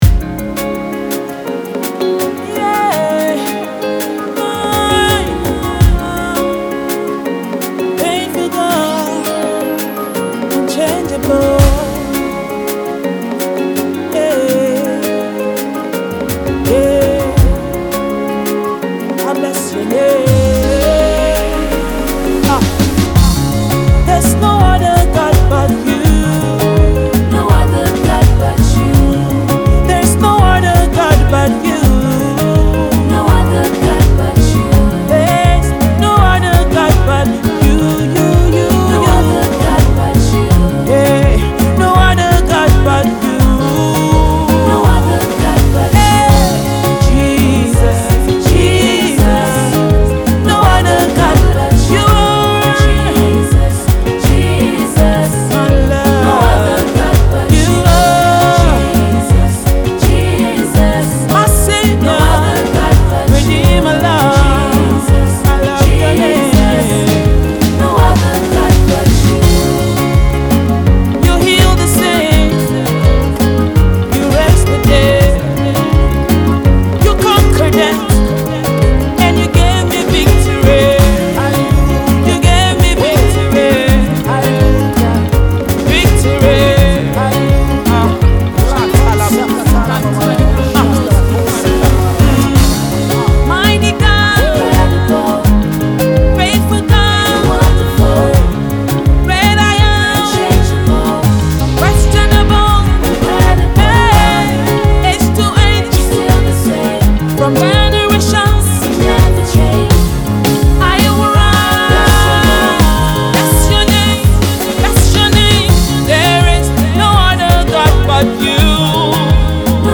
Sensational Gospel music star and angelic sound creator